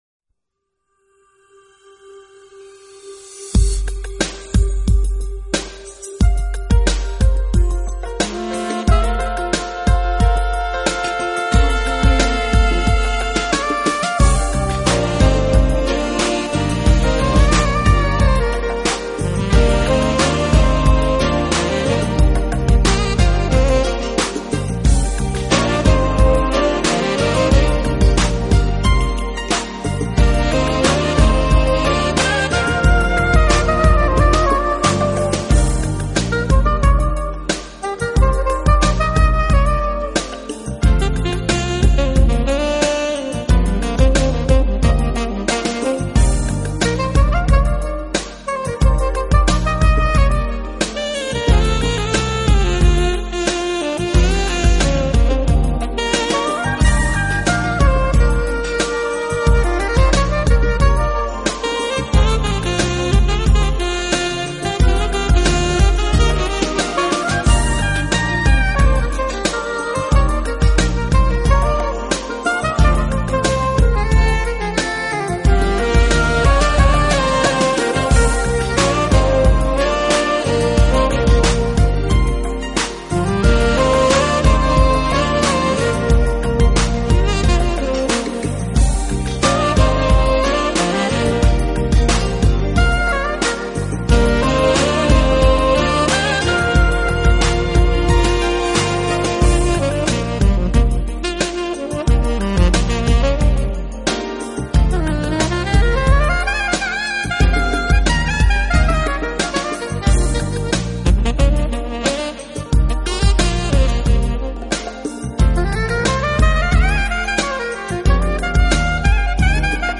音乐类型:  Smooth Jazz